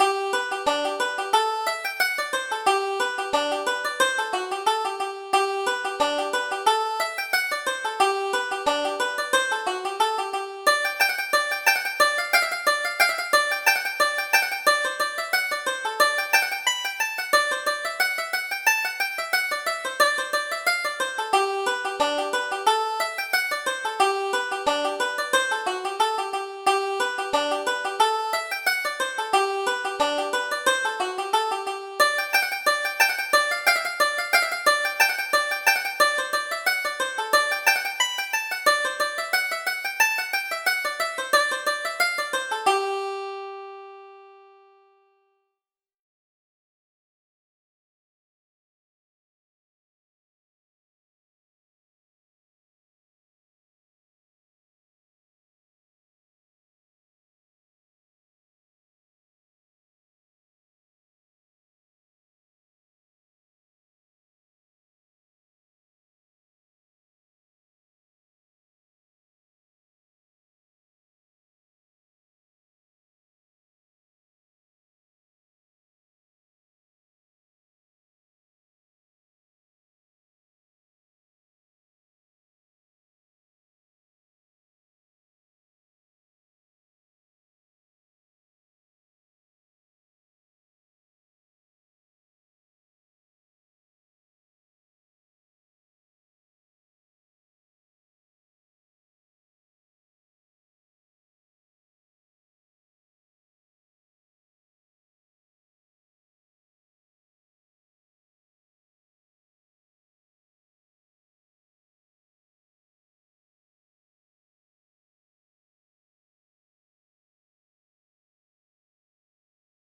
Reel: The Milliner's Daughter